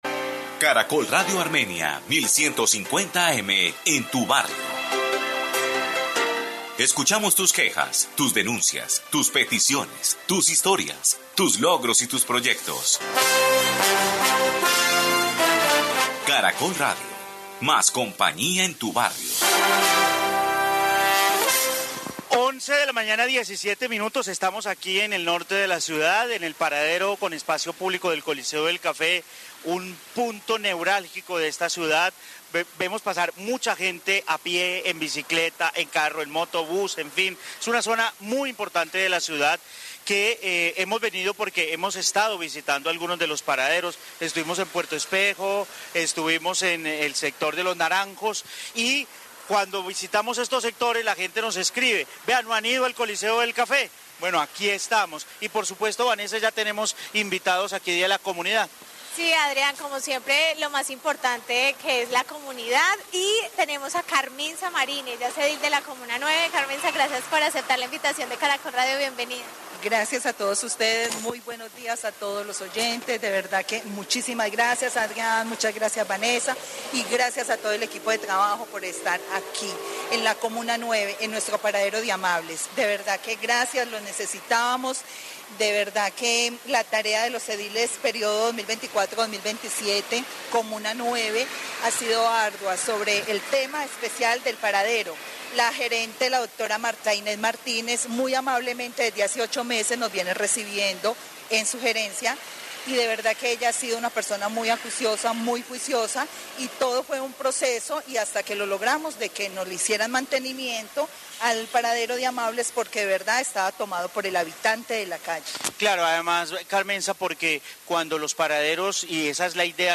Caracol Radio originó el noticiero del mediodía desde el norte de Armenia en el paradero con espacio público del coliseo del café